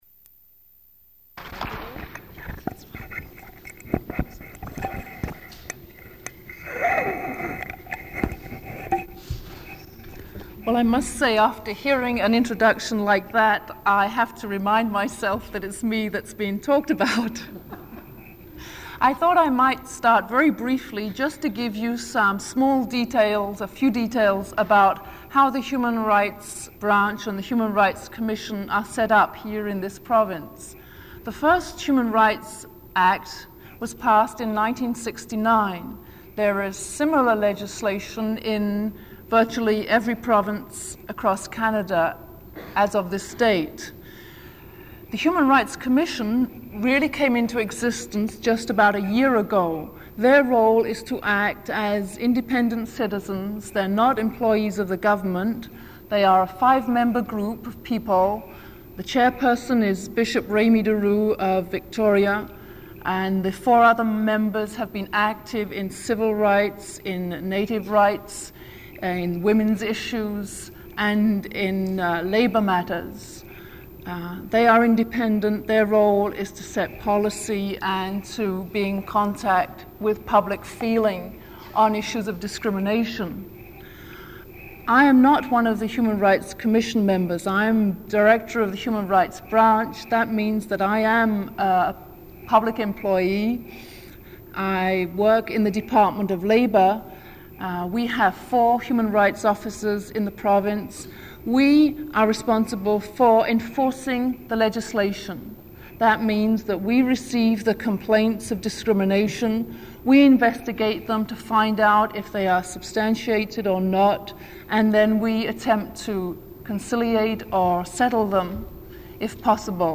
Original audio recording available in the University Archives (UBC AT 415).